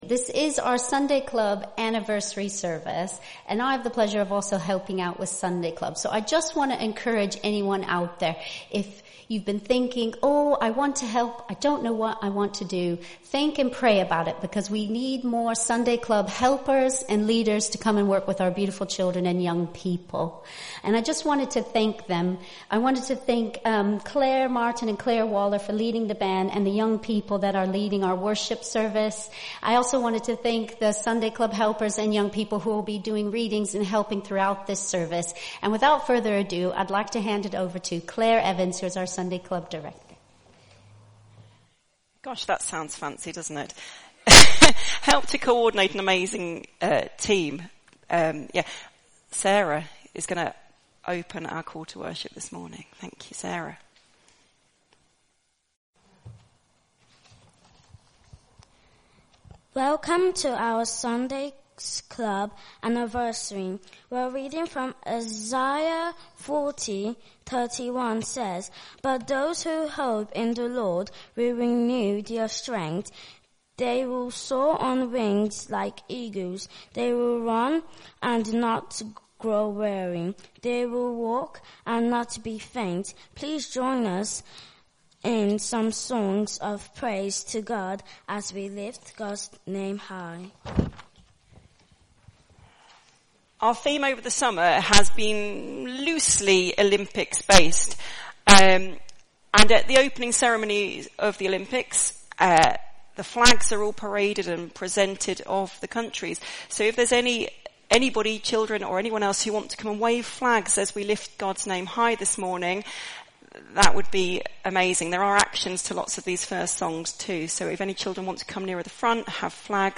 The young people take a large part in this service, from readings to playing in the band.
Hebrews 12:1-2 Service Type: Celebration Today is our Sunday Club Anniversary service
The service without the music (and video) is available as an audio file.